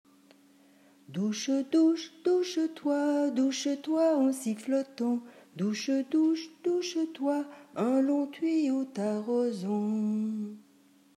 Merci de votre indulgence pour les enregistrements improvisés !
Même mélodie et même mouvement de base que la lettre a.
Chanson :